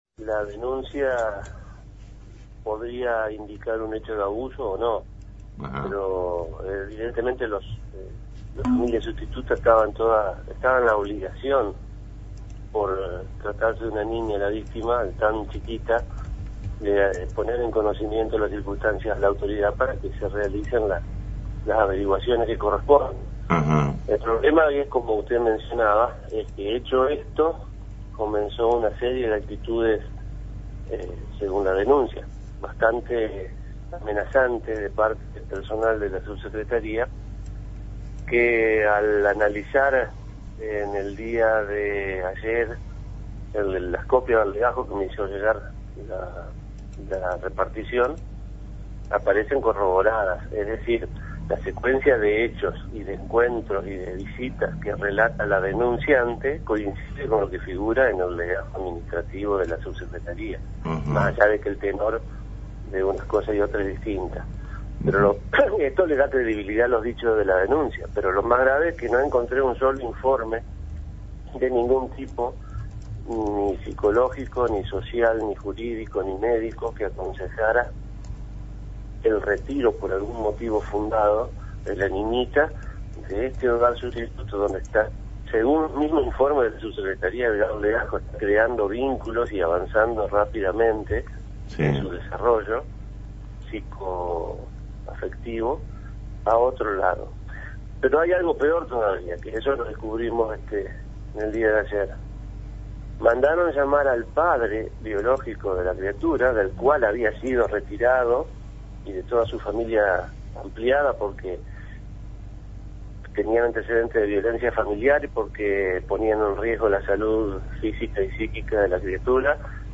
Audio del Fiscal Aldo Gerosa en diálogo con LT10
Fiscal-Gerosa.mp3